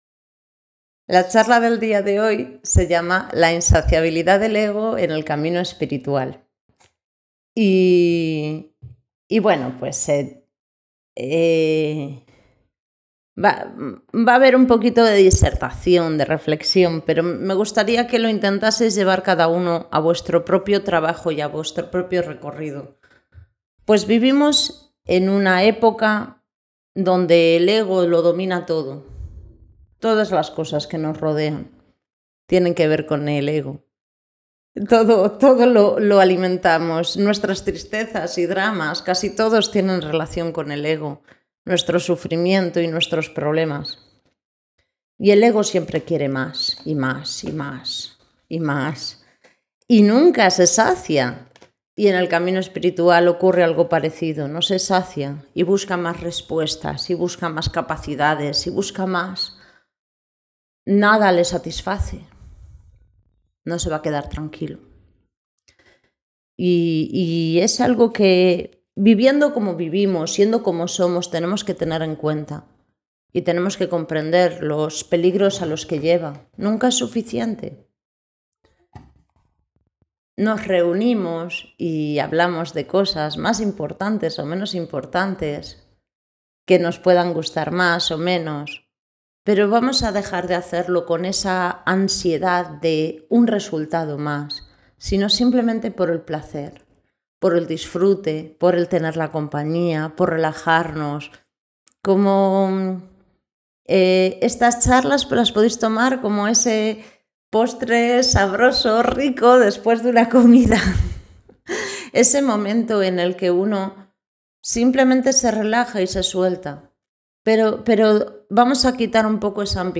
La insaciabilidad del ego en el camino espiritual Preguntas varias Conferencia en el grupo de trabajo